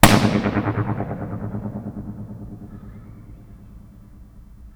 berlin_tunnel_ir.wav